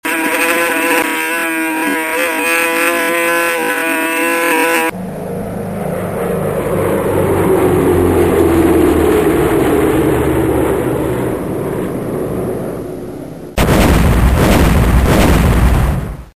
Journée de la mouche Gertrude téméraire et prétentieuse se prenant tout à coup pour un bombardier et brusquement supportant les conséquences de son inconséquence que l'on devine désastreuses  : cliquez pour entendre et constater :
MoucheGertrude.mp3